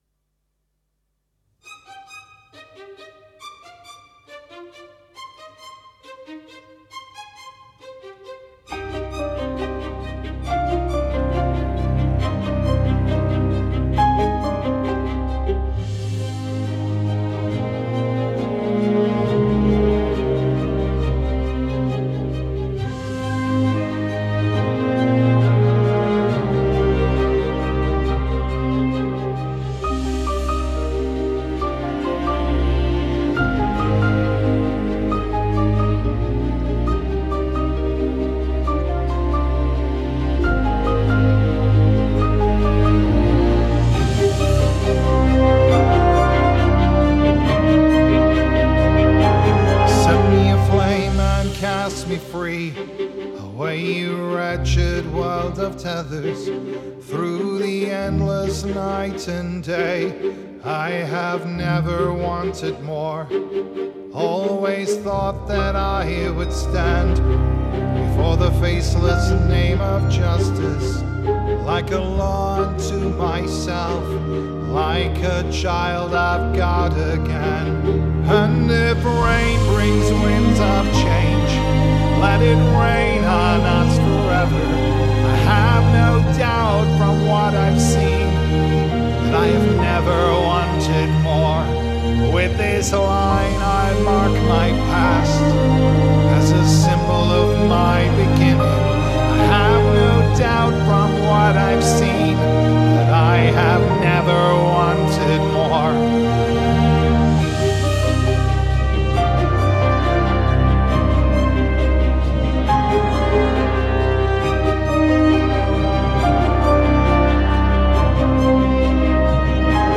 arranged purely for voice and orchestra
Genre: Classical, Electronic